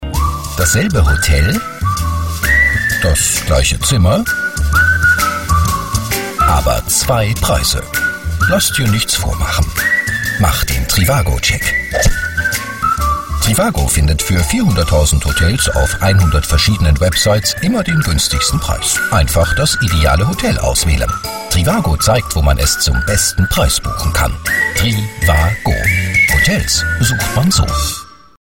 Male
Burger King Sat1 Teaser Shakira Arte Themenabend Spot Trivago Nintendo Imagevideo